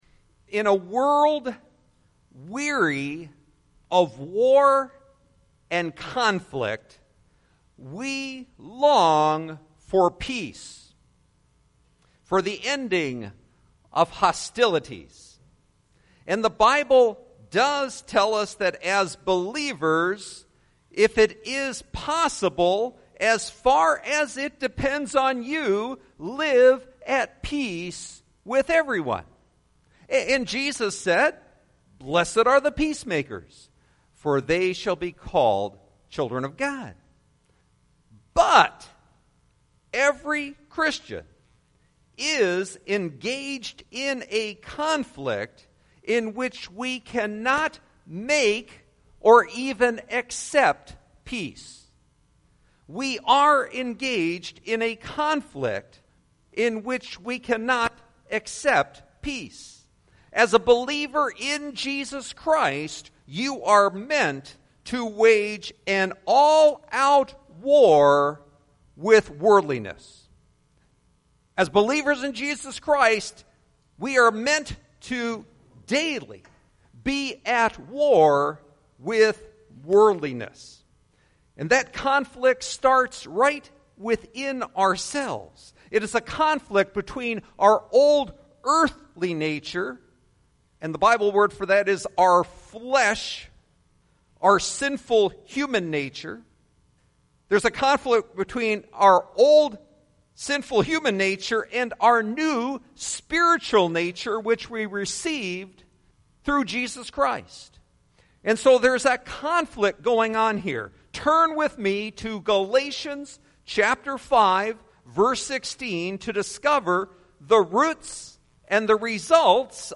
Warnings From Heaven Listen To Sermon